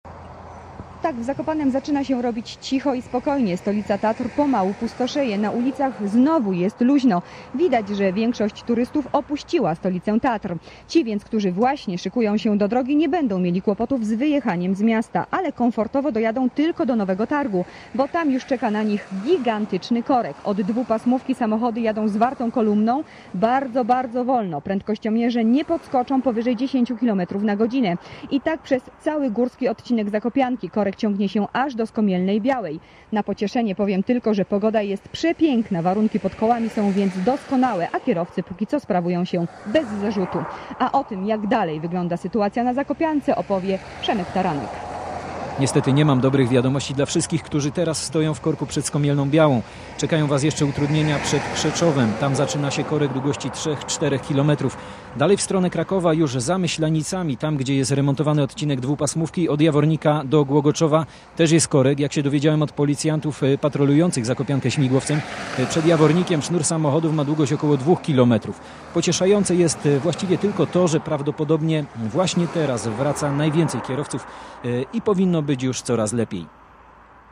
Relacja reporterów Radia Zet (600Kb)